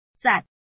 臺灣客語拼音學習網-客語聽讀拼-南四縣腔-入聲韻
拼音查詢：【南四縣腔】zad ~請點選不同聲調拼音聽聽看!(例字漢字部分屬參考性質)